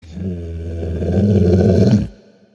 Звук рычания мультяшного пса Cartoon Dog